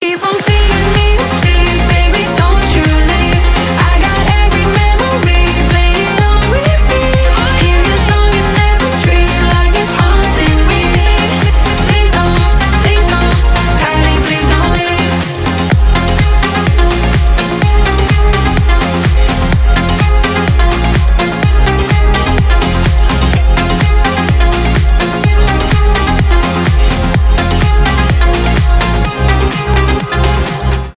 танцевальные , поп , ремиксы